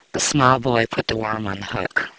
M: Male, F: Female